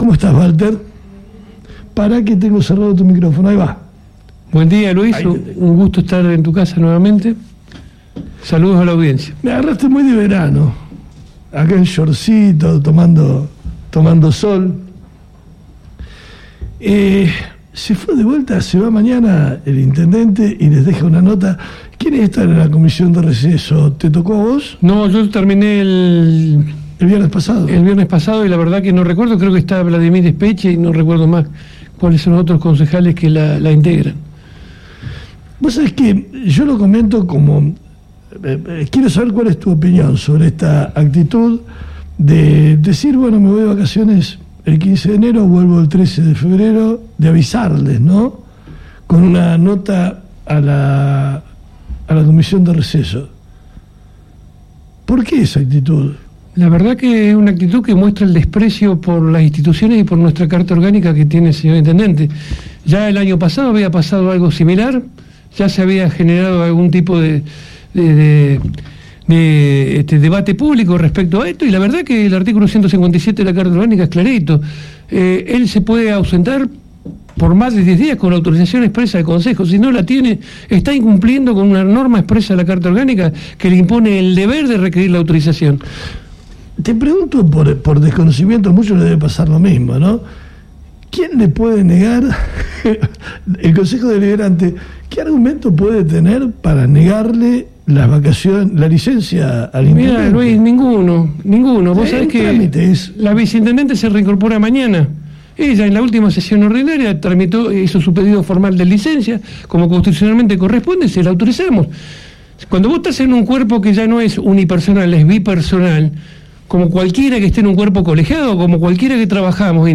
Recibimos en nuestros estudios al concejal de Somos Fueguinos, Valter Tavarone para hablar sobre las vacaciones del intendente y los temas que están pendientes en la ciudad.